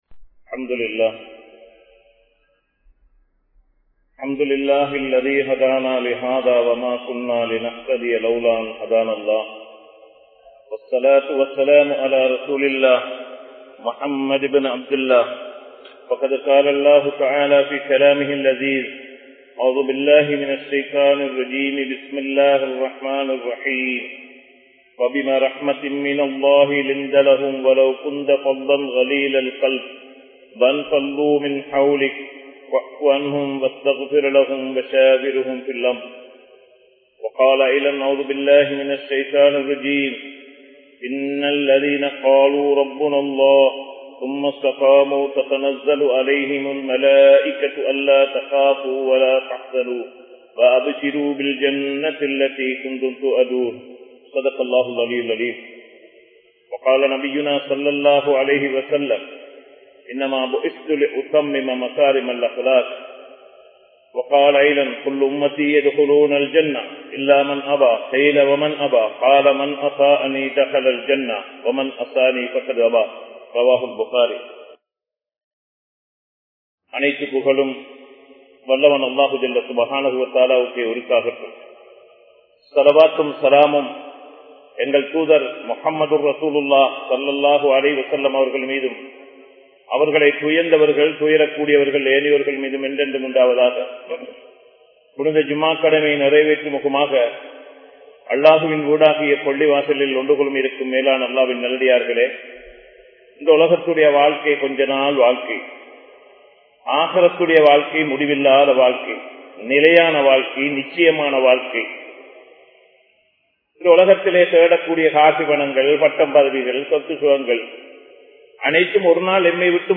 Thanthaien Rahasiyam Pillaihal(தந்தையின் ரகசியம் பிள்ளைகள்) | Audio Bayans | All Ceylon Muslim Youth Community | Addalaichenai
Negombo, Kamachoda Jumua Masjith